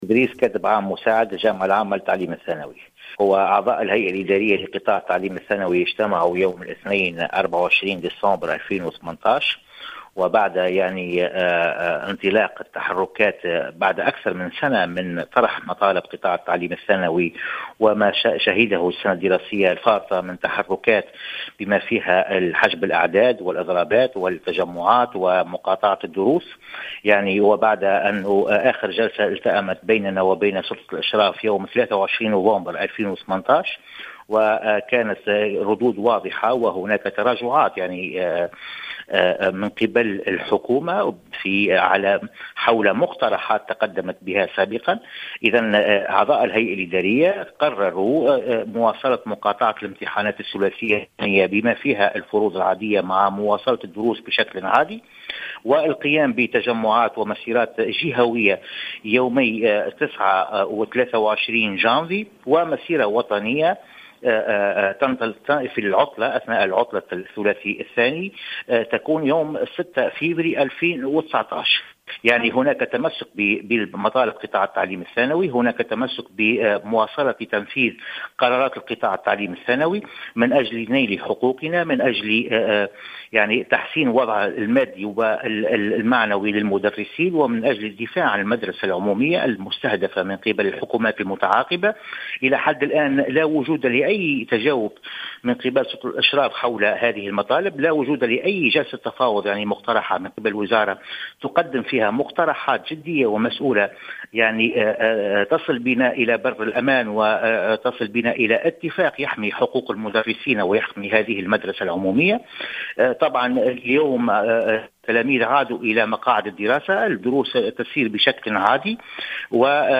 في تصريح للجوهرة "اف ام"